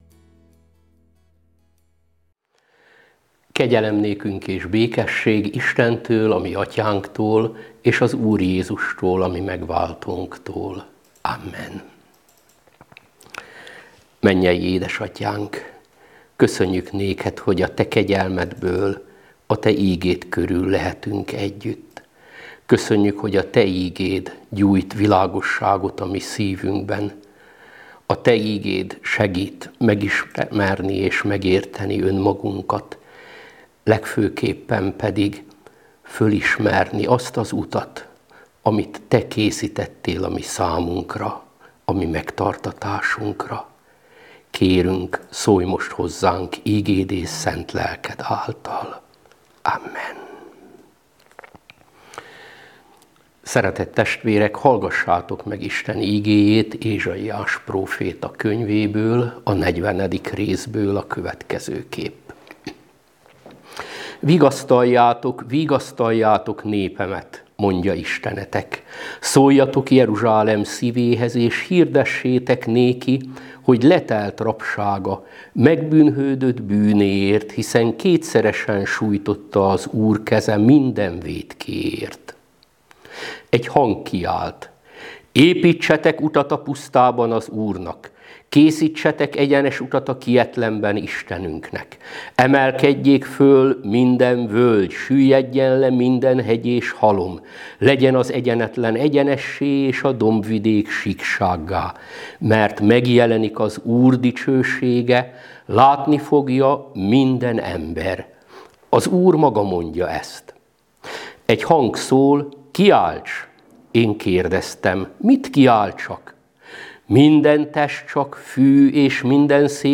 A hangfelvételen meghallgatható az áhítat.
ahitat.mp3